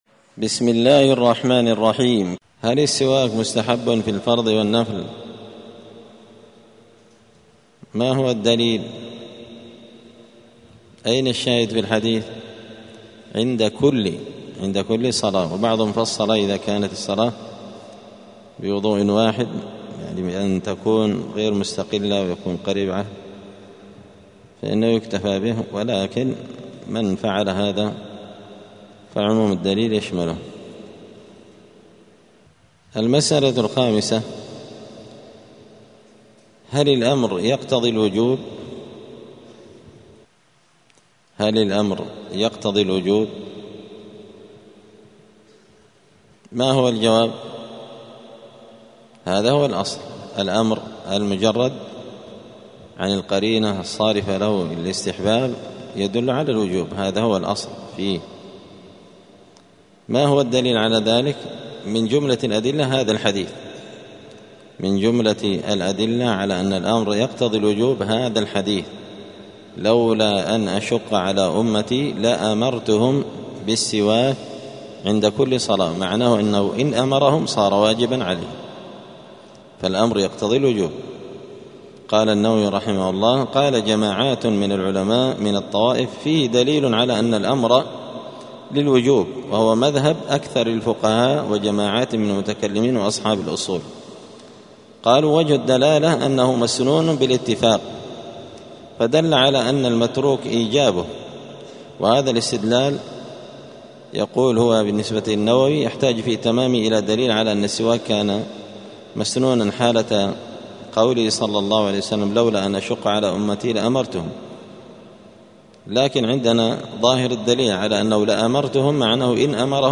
دار الحديث السلفية بمسجد الفرقان بقشن المهرة اليمن
*الدرس السادس عشر (16) {باب السواك هل يستخدم السواك باليمنى أو اليسرى…}*